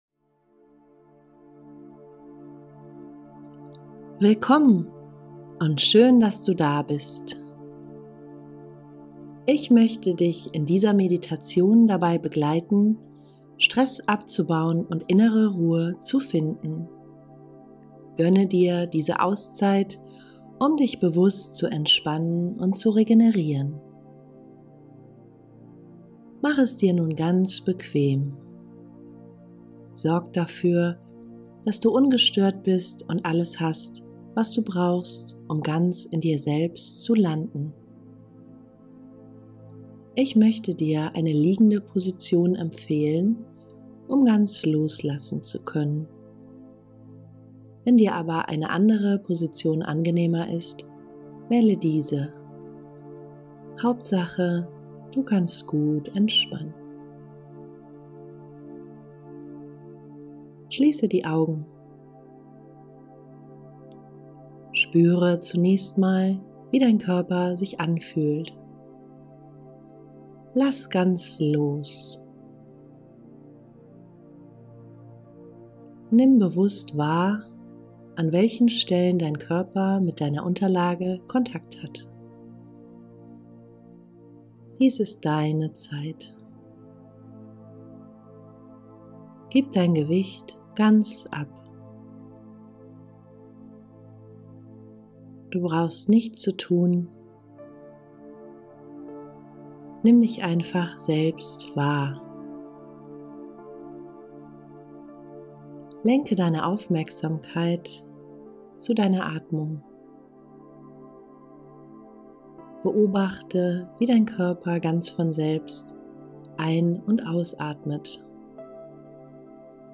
Beschreibung vor 1 Jahr Diese geführte Meditation hilft dir, Stress loszulassen und innere Ruhe zu finden. Ganz gleich, was dich gerade beschäftigt, gönne dir eine kleine Auszeit von etwa 20 Minuten, lasse den Alltag hinter dir und begib dich an einen Ort der Stille in dir, wo du stets auftanken und neue Kraft schöpfen kannst.